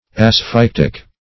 Asphyctic \As*phyc"tic\, a. Pertaining to asphyxia.